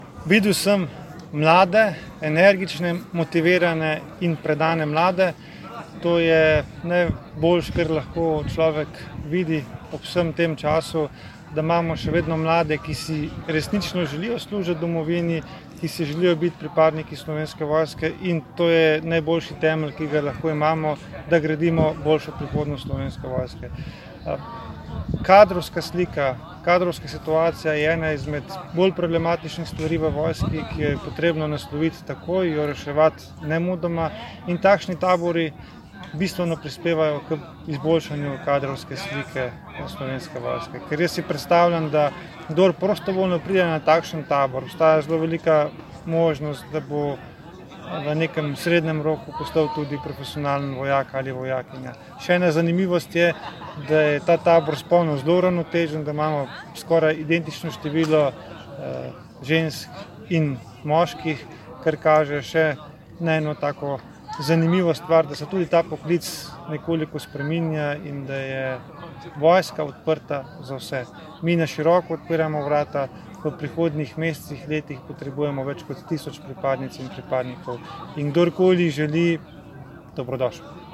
Minister za obrambo mag. Matej Tonin je danes, 9. julija, obiskal Vojašnico Franca Uršiča v Novem mestu in si ogledal izvajanje tabora »MORS in mladi 2020«, ki tokrat poteka že 15. leto zapored, udeležuje pa se ga skupaj 254 dijakov in osnovnošolcev.
Zvočni posnetek nagovora ministra Tonina (MP3)